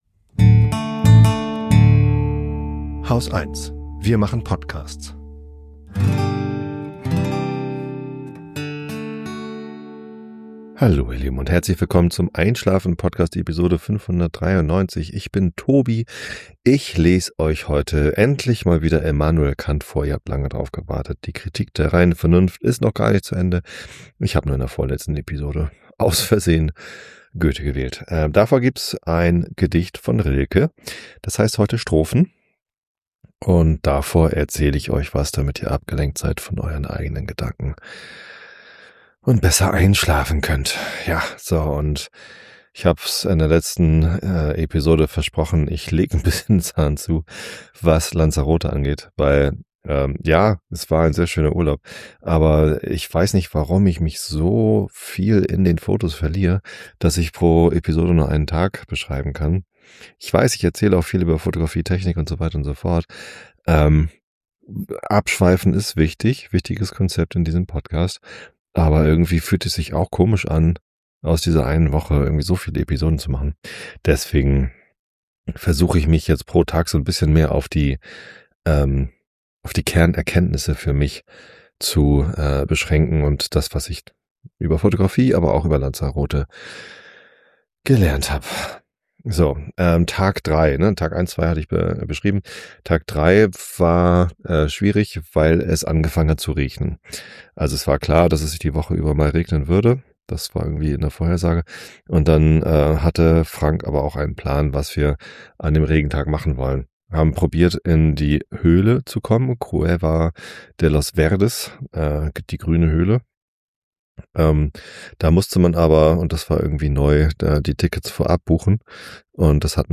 Die Kritik der reinen Vernunft ist vollständig vorgelesen. Und Lanzarote ist auch durch.